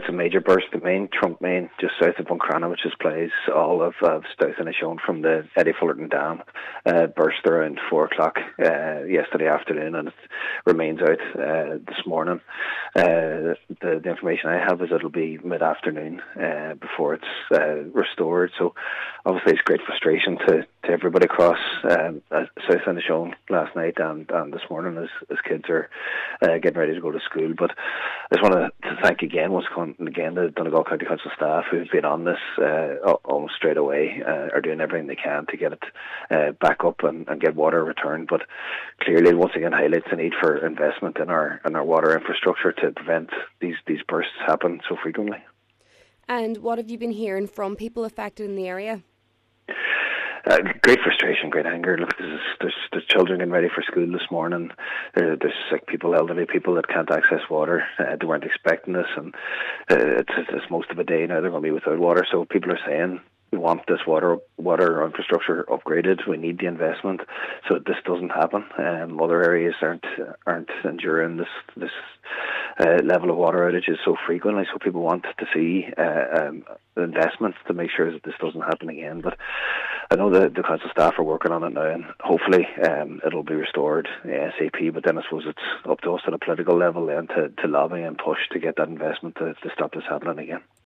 Cathaoirleach of the Inishowen Municipal District, Cllr Jack Murray, acknowledges the anger felt by many.